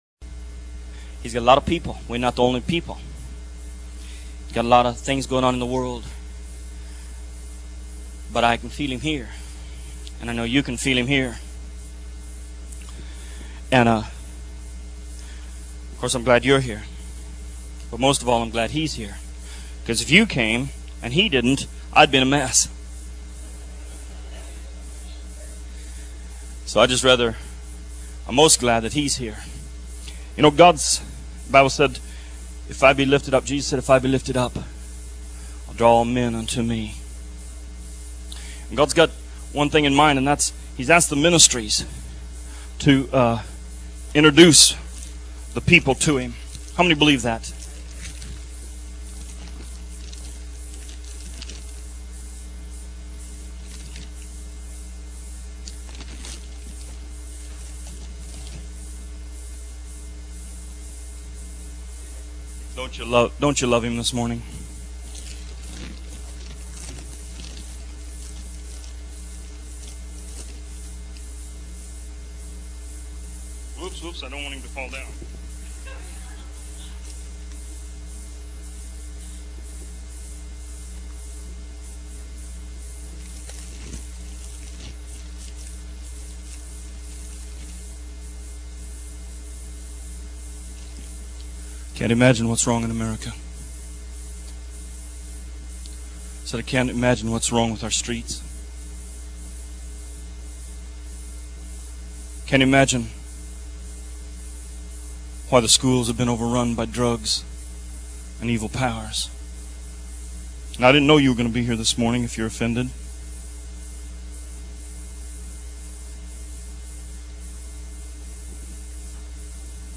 Series Sermon